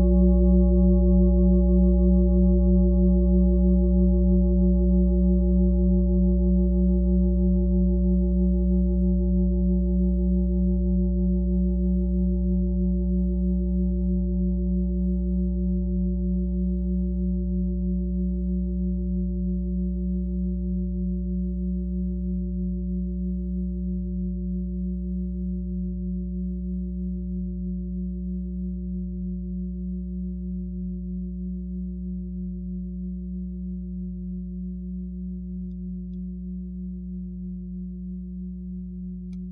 Fuß-Klangschale Nr.6
Diese große Klangschale wurde in Handarbeit von mehreren Schmieden im Himalaya hergestellt.
(Ermittelt mit dem Gummischlegel)
fuss-klangschale-6.wav